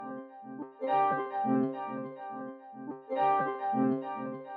• delayed synth harp chill sequence - Cm.wav
delayed_synth_harp_chill_sequence_-_Cm_cud.wav